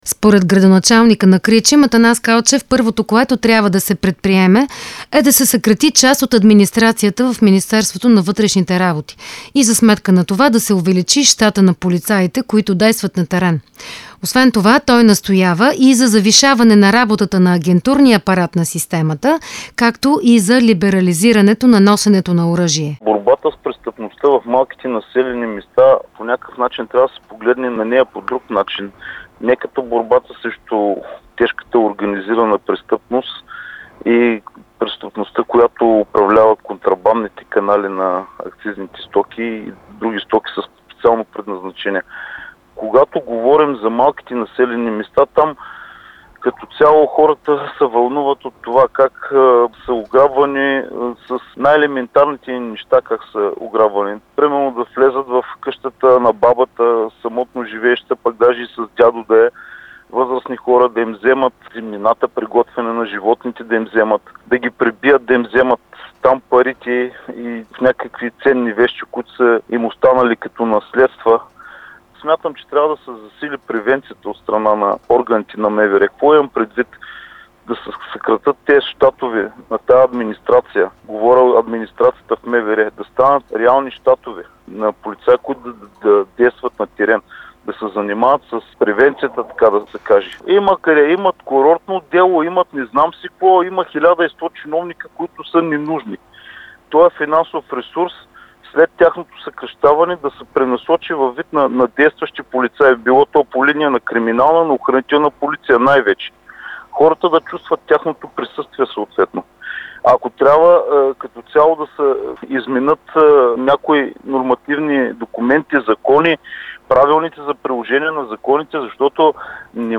За незабавните мерки, които трябва да се предприемат, за да се овладеят престъпленията в малките общини е репортажът с кметовете на Кричим и Асеновград.